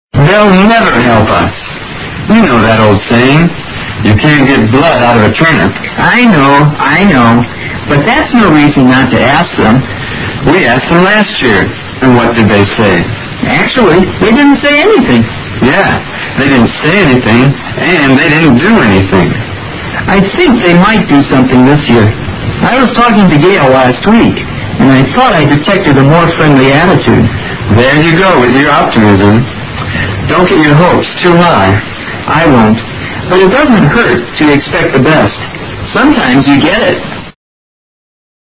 Dialogue 17